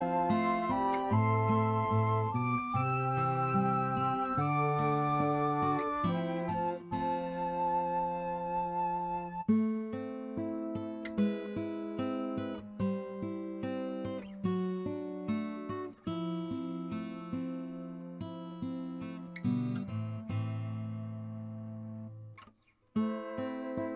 Minus Electric Guitars Rock 8:06 Buy £1.50